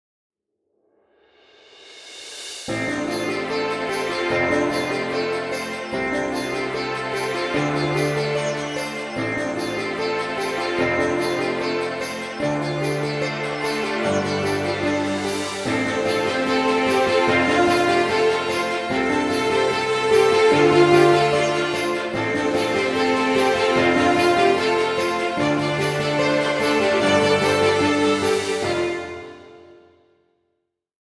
Background Music Royalty Free.